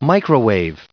microwave_en-us_recite_stardict.mp3